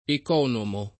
ek0nomo] agg. e s. m. — sim. i cogn. Economi, Economo — passato in Austria come von Economo [ted. fon ekon1omo], forse per tramite it. quanto a grafia, l’originario cogn. gr. ἐξ Οἰκονόμων / ex Ikon÷mon [gr. mod. HkS ikon0mon]